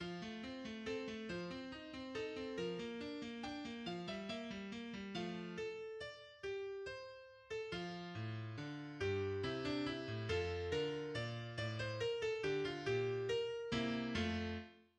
KeyF major
StyleClassical period
The first movement is in a classical sonata form, with repeats for the exposition, the development and recapitulation.[2] There are two basic characteristics exemplified in the first movement: the song-like themes and the combination of styles.
The first theme of the exposition begins with a right hand melody over a left hand Alberti bass figure.[2] Next, there is a short melody with the left hand echoing it, while the right hand plays a two-part phrase that includes a Mordent.